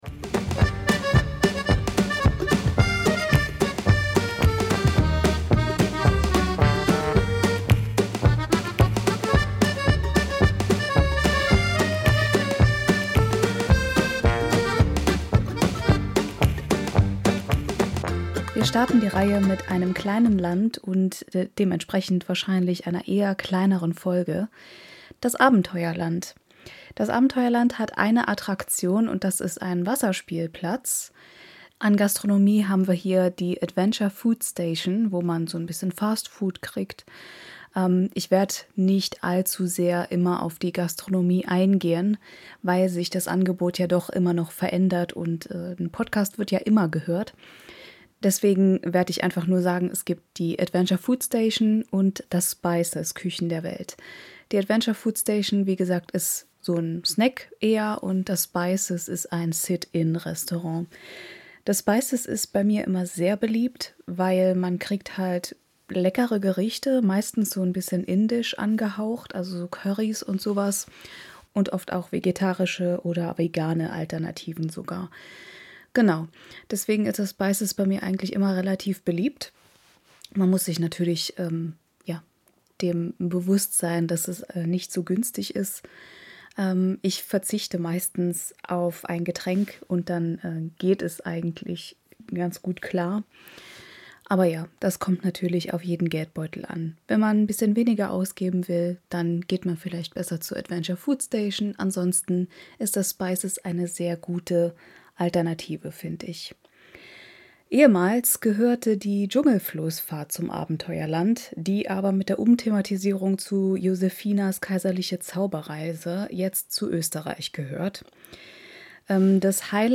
Music by Tunetank from Pixabay